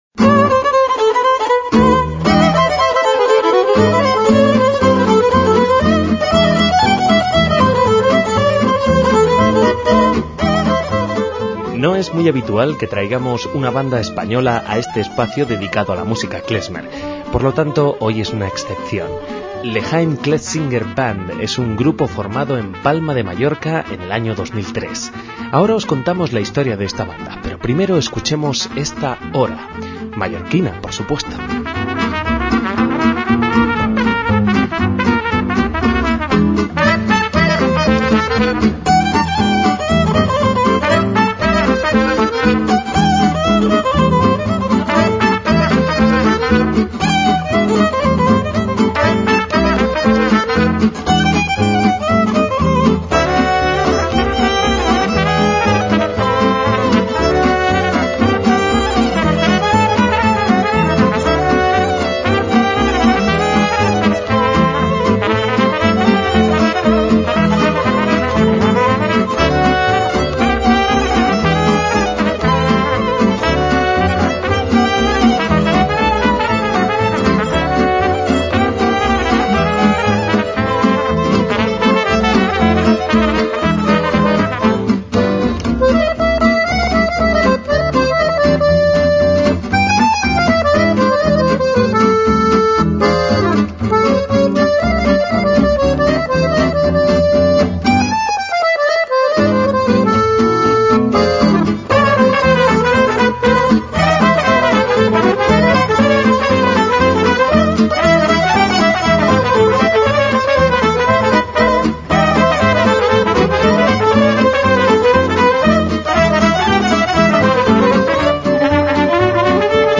MÚSICA KLEZMER
combinan música klezmer y zíngara